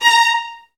VIOLINS..3-R.wav